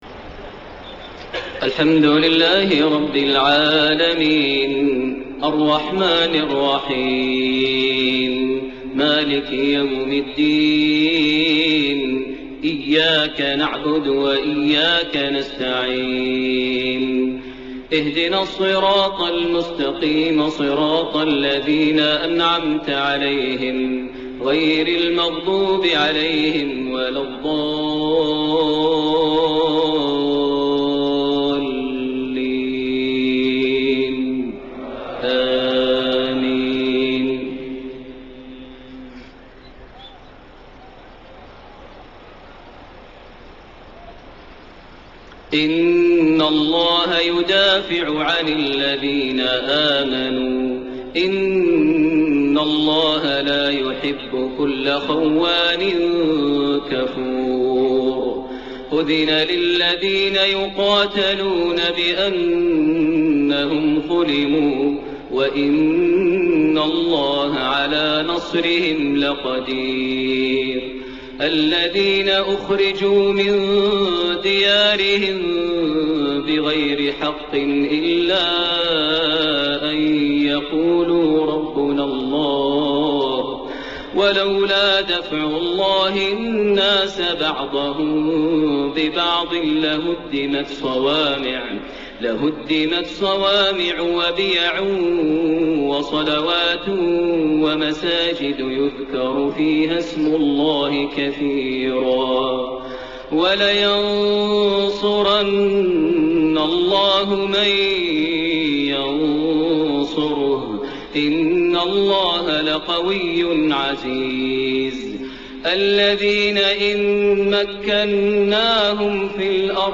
Isha prayer from surah Al-Hajj > 1433 H > Prayers - Maher Almuaiqly Recitations